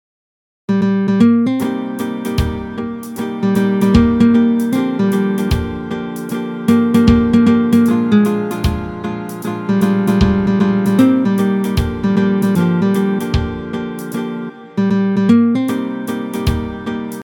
Démo guitare en ternaire